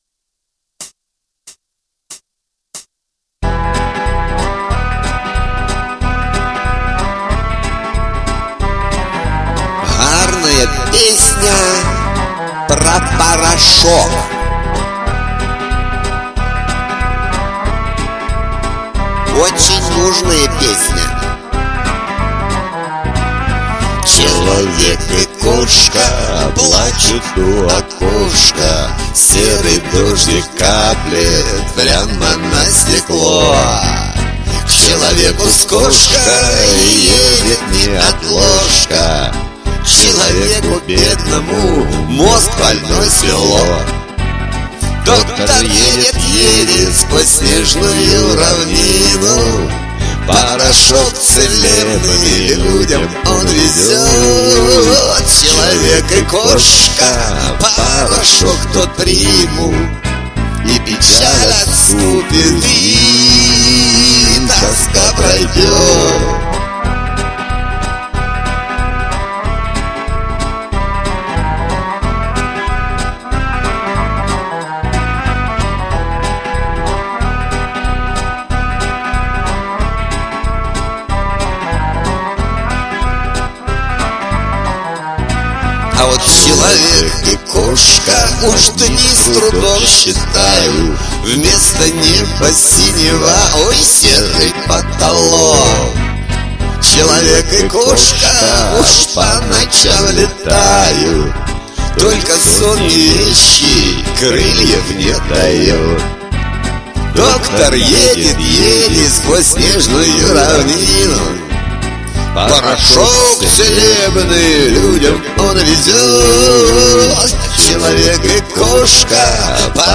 Наличие 2-х голосов у обоих мне понравилось...
Твой, естественный, голос слух ласкает!